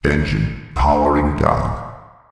CyclopsEngineOff.ogg